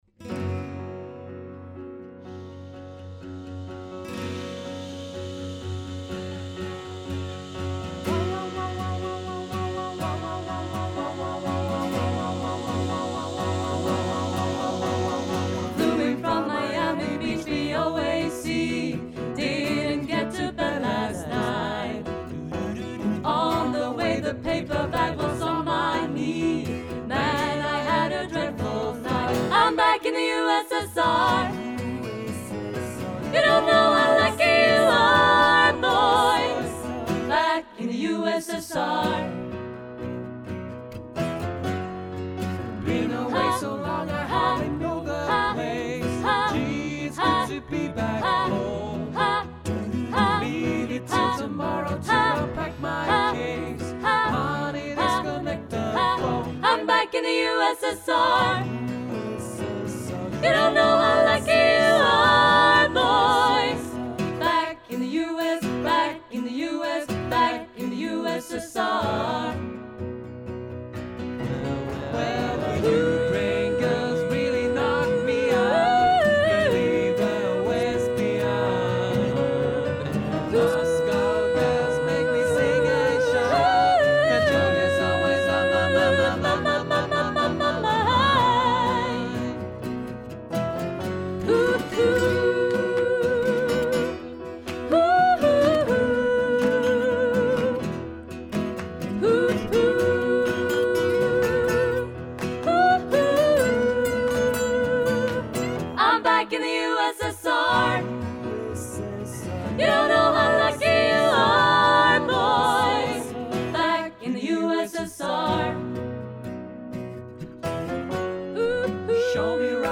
för 4-stämmig blandad kör